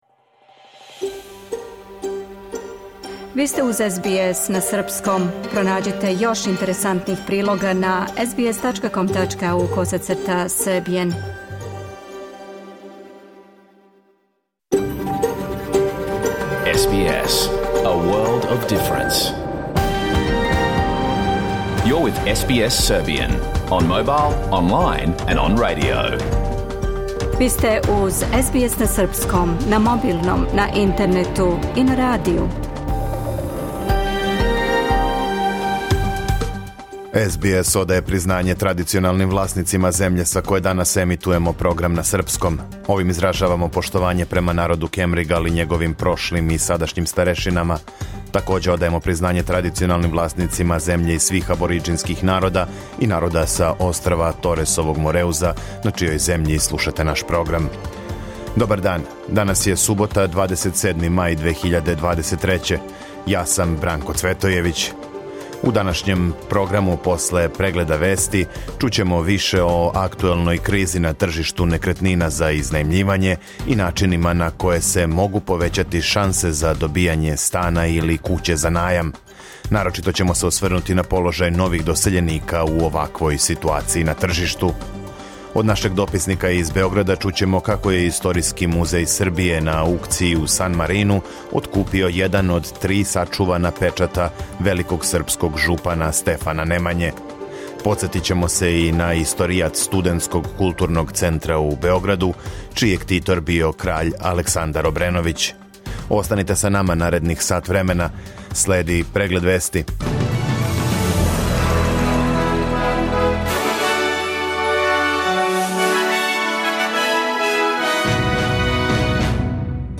Програм емитован уживо 27. маја 2023. године
Ако сте пропустили данашњу емисију, можете да је слушате у целини као подкаст, без реклама.